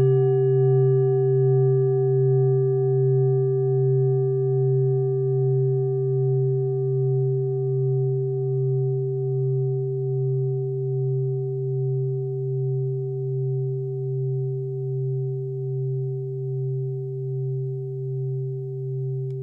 Klangschale TIBET Nr.7
Klangschale-Durchmesser: 21,1cm
Sie ist neu und ist gezielt nach altem 7-Metalle-Rezept in Handarbeit gezogen und gehämmert worden..
(Ermittelt mit dem Filzklöppel oder Gummikernschlegel)
In unserer Tonleiter ist das in der Nähe vom "Fis".
klangschale-tibet-7.wav